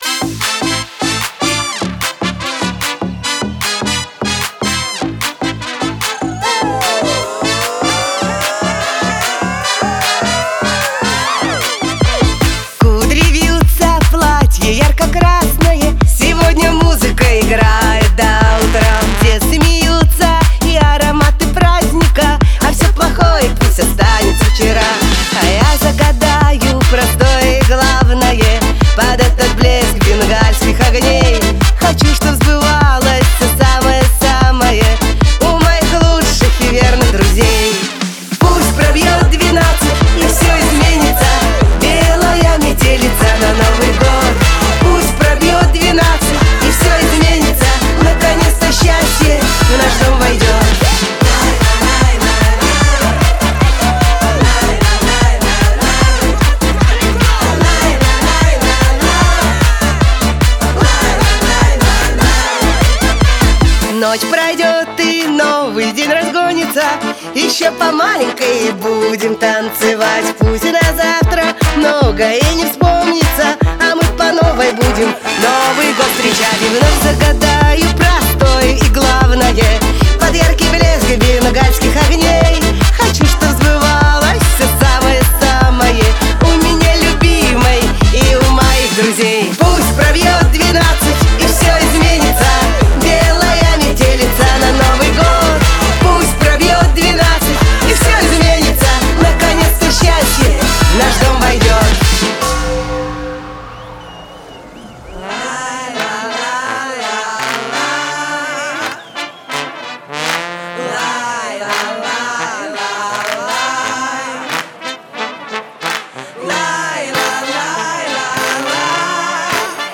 Звучание отличается живыми мелодиями и задорными ритмами